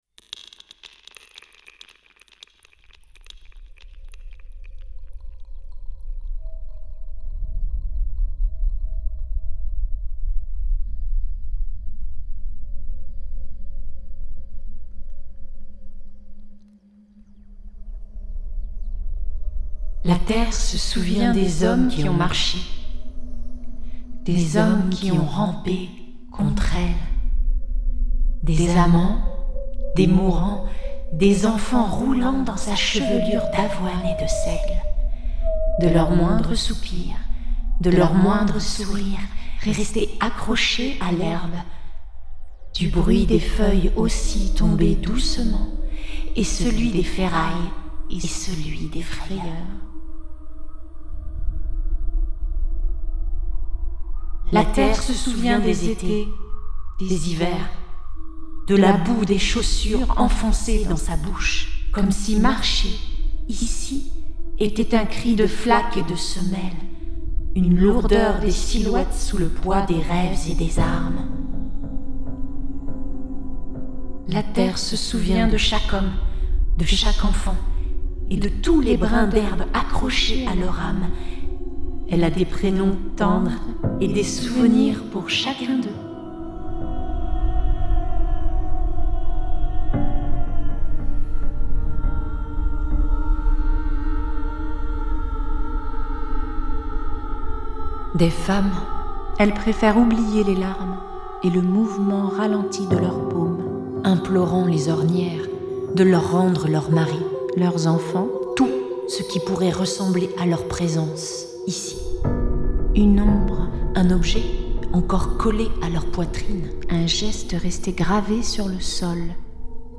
Poésie sonore